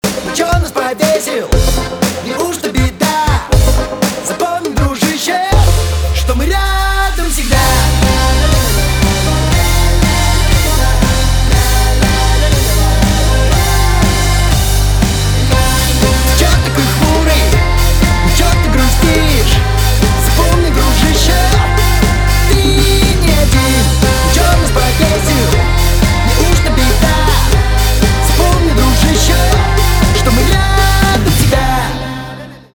русский рок
гитара , барабаны